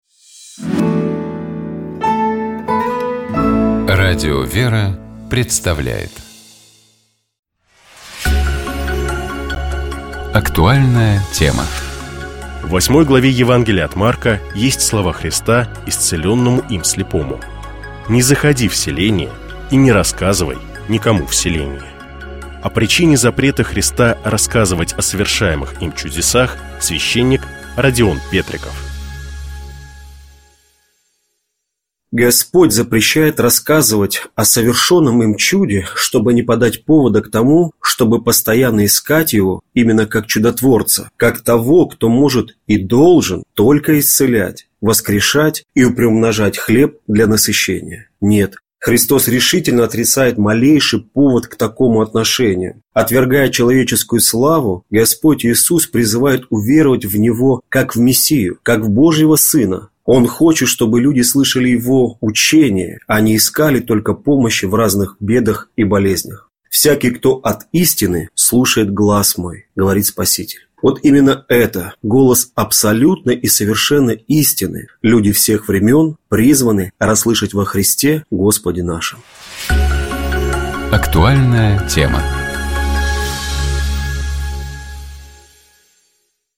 О причине запрета Христа рассказывать о совершаемых Им чудесах — священник